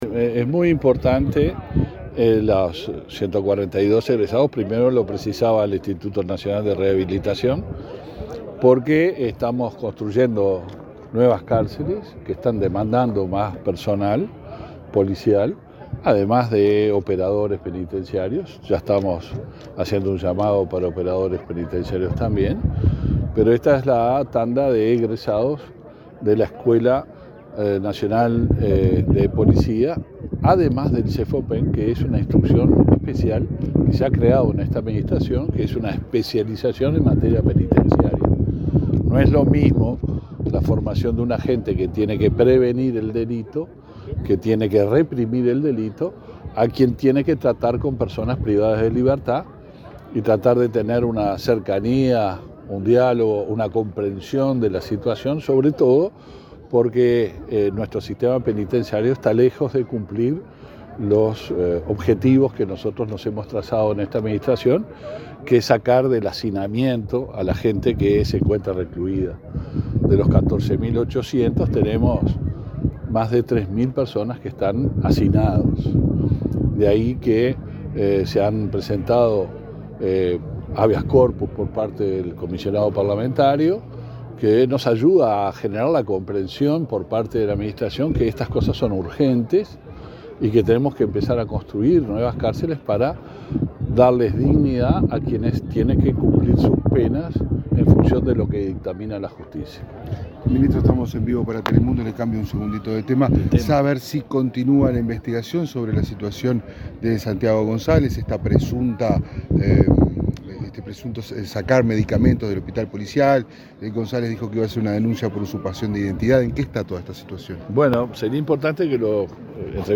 Declaraciones del ministro del Interior, Luis Alberto Heber
Luego dialogó con la prensa.